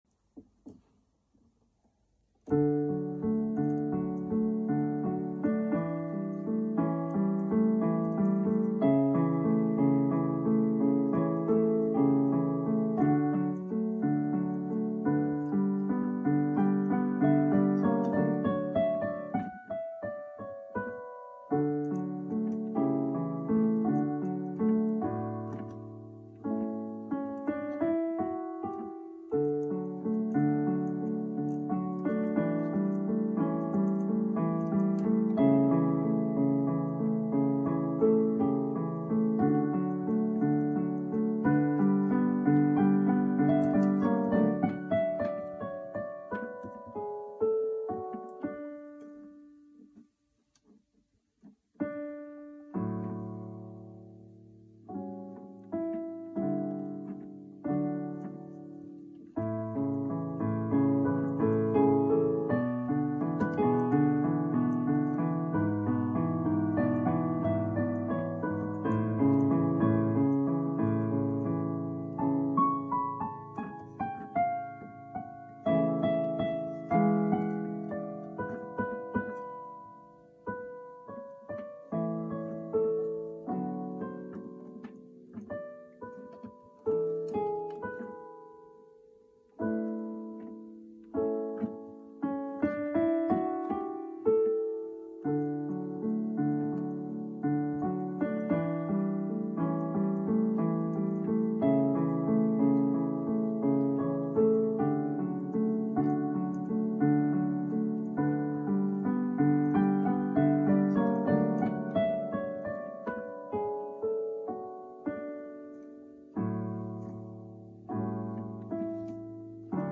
Zongorázás & szerkesztés között /Tra il pianoforte & redazione: Több mint 50 év után találtam rá Pleyel és Benda ezen szonatináira / L'ho ritrovati dopo più di 50 anni >> és tegnap éjjel több mint fél évszázad után igyekeztem újra eljátszani, amiben a felvételkor készített lámpaláz csak akadályozott... / e ieri notte dopo di più di mezzo secolo ho cercato di risuinare, ma nella registrazione la forte ansia da riflettori/registrazione m'ha fortemente fortemente ostacolata...